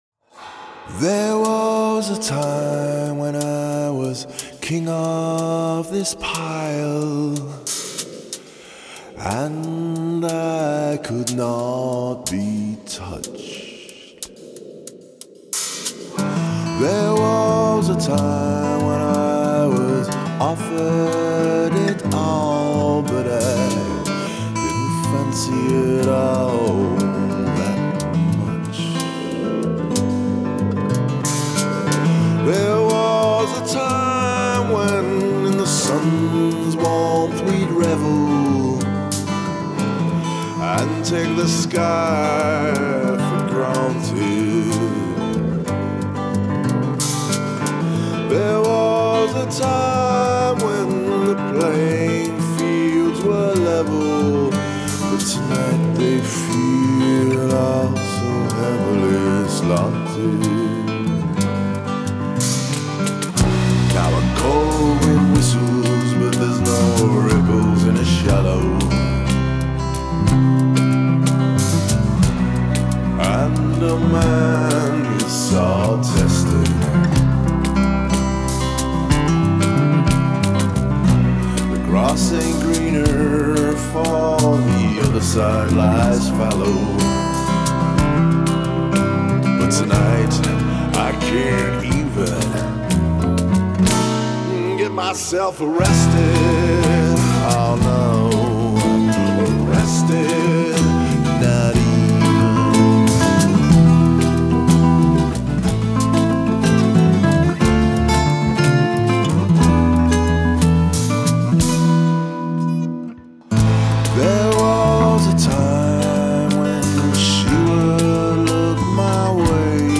Acoustic Guitars,Electronica & Voices